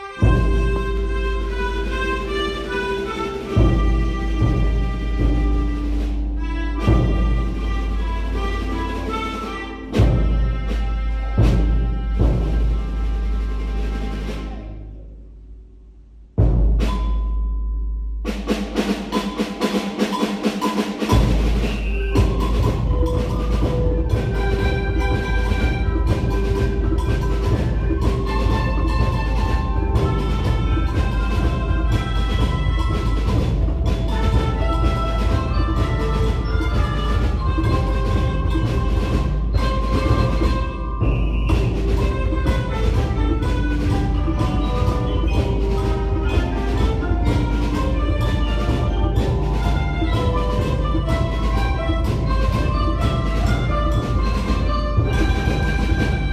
プレ発表　連合音楽会
音楽朝会で５年生が
合奏曲「風になりたい」を披露しました。